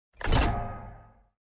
ui_interface_22.wav